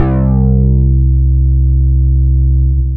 LO BASS.wav